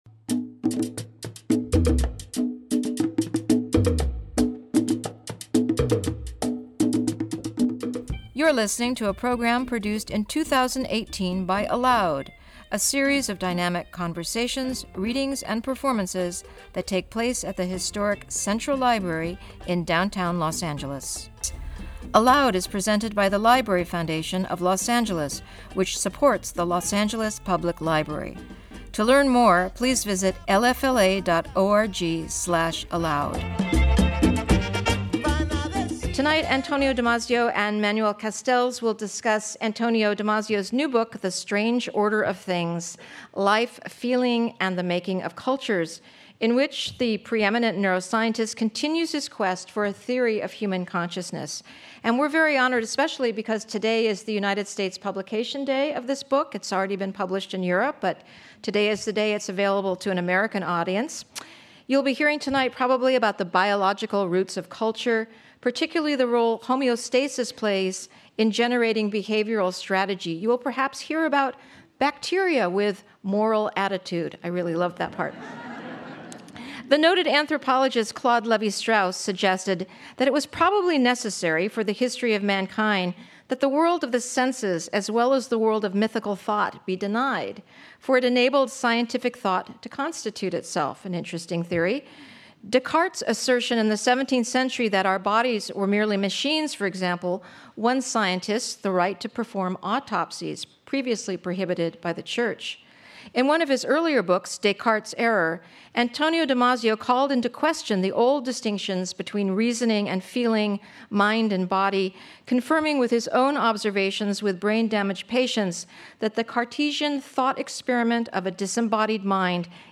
Antonio Damasio In Conversation With Manuel Castells
In his newest book The Strange Order of Things , Damasio traces the need for cultures back to one-cell organisms, long before there were nervous systems and conscious minds. Damasio will be joined by Manuel Castells, one of the world’s leading sociologists, for a fascinating conversation on the origins of life, mind, and culture that spans the biological and social sciences to offer a new way of understanding the world and our place in it.